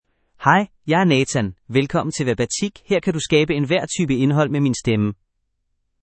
Nathan — Male Danish AI voice
Nathan is a male AI voice for Danish (Denmark).
Voice sample
Listen to Nathan's male Danish voice.
Nathan delivers clear pronunciation with authentic Denmark Danish intonation, making your content sound professionally produced.